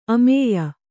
♪ amēya